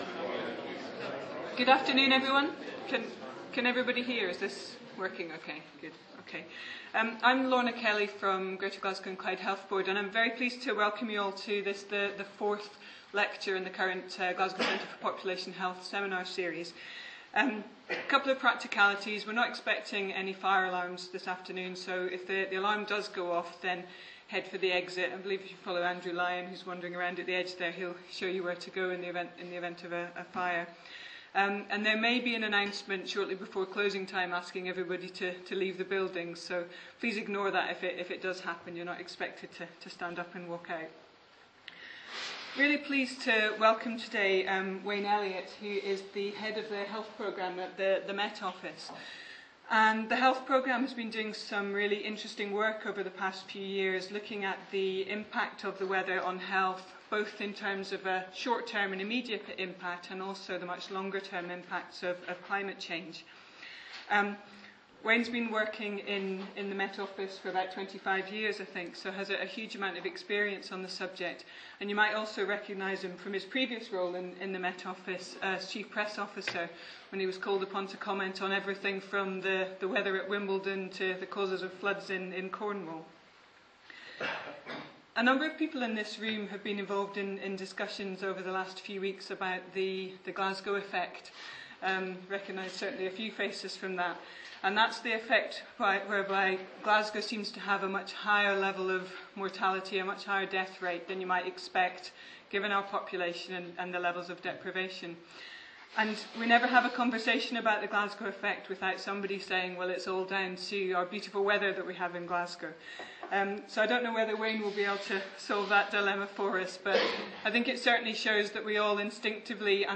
Seminar
The Lighthouse, Glasgow, United Kingdom